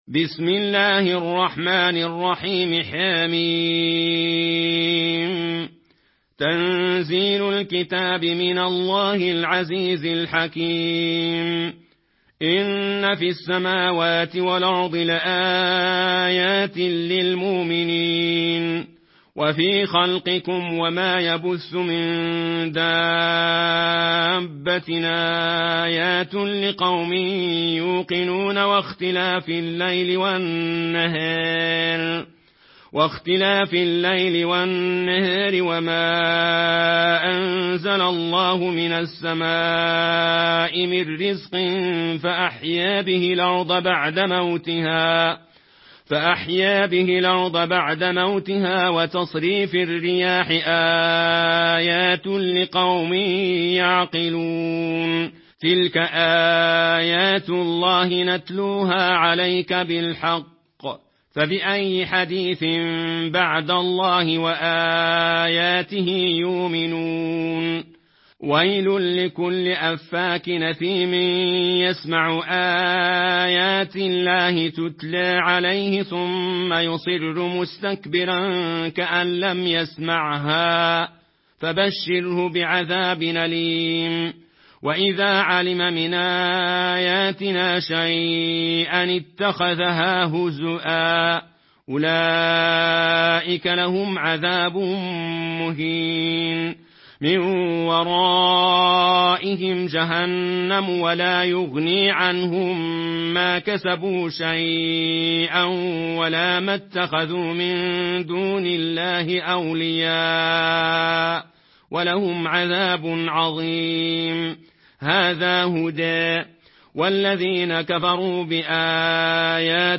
Une récitation touchante et belle des versets coraniques par la narration Warsh An Nafi.
Murattal Warsh An Nafi